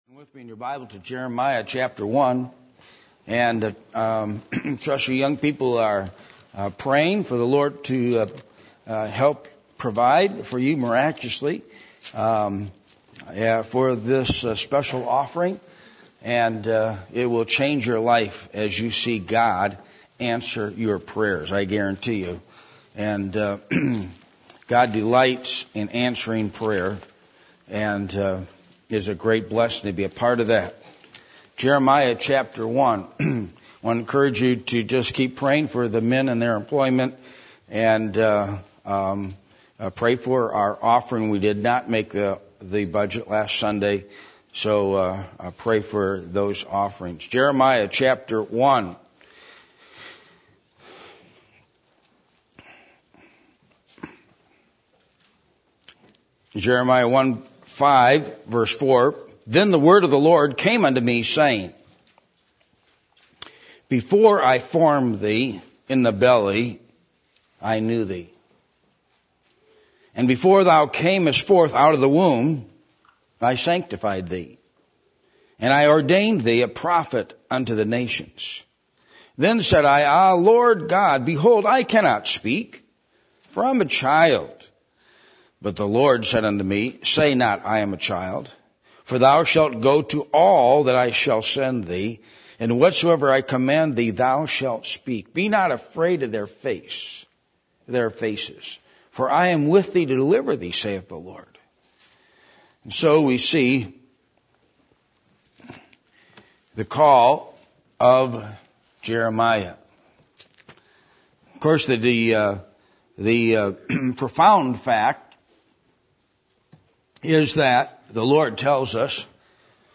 Jeremiah 1:1-9 Service Type: Midweek Meeting %todo_render% « The Characteristics Of False Teachers